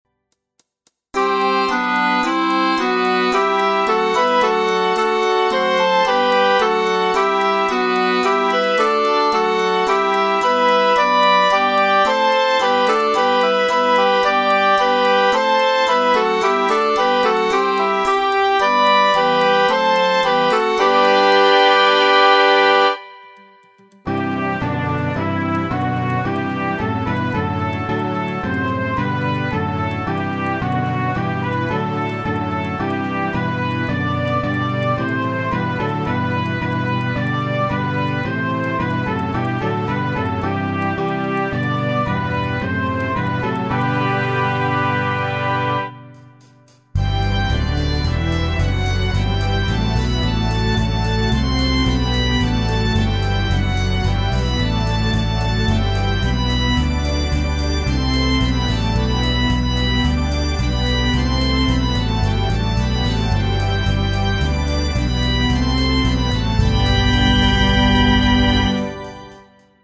Keyboard, 2-stimmig G-Dur
herr_lass_deines_geistes_zungen_keyb.mp3